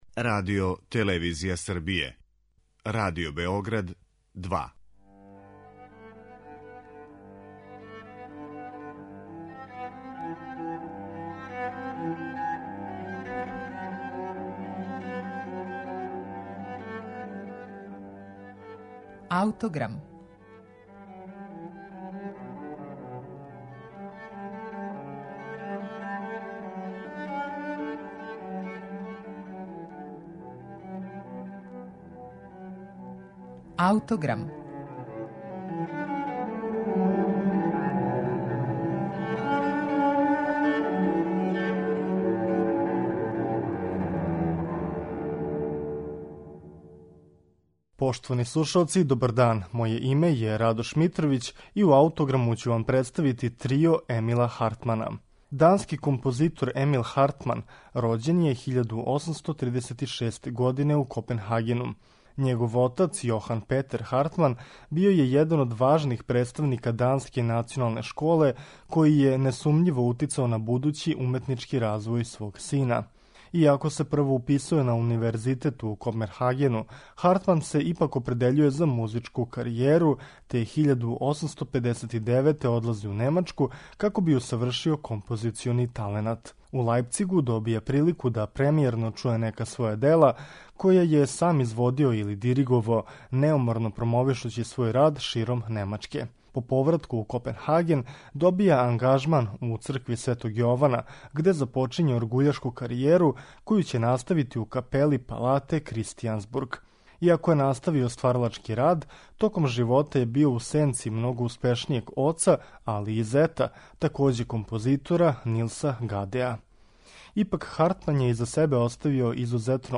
У овом делу, дански уметник је показао изузетно познавање техника виолине, виолончела и клавира, који је и сам свирао, као и специфичан музички рукопис. Ову композицију ћемо вам представити у извођењу трија Ескар.